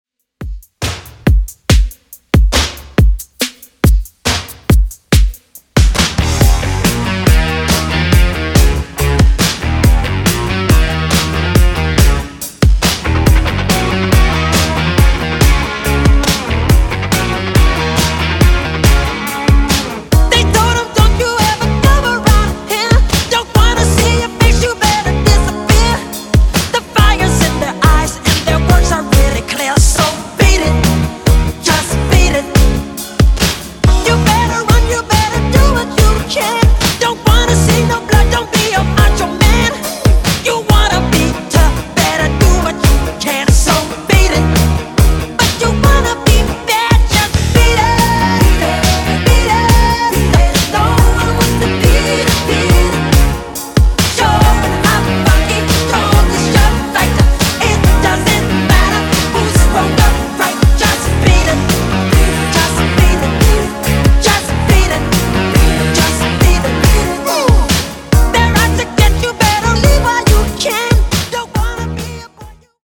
Genres: 2000's , R & B , RE-DRUM
Clean BPM: 100 Time